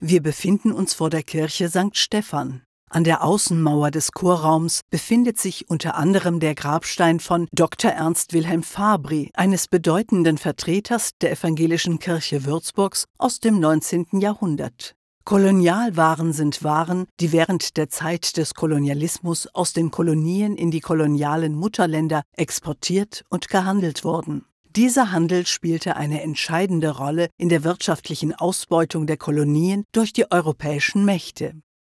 Mit ihrer tiefen, samtigen Charakterstimme gibt die Sprecherin jedem Script einen individuellen Touch.
Sprechprobe: Sonstiges (Muttersprache):
With her deep, velvety character voice, the speaker gives every script an individual touch.
Writers and listeners alike appreciate the varied realisation of her texts and the outstanding audio quality of the recordings produced in her own studio.
Audioguide-Museum_0.mp3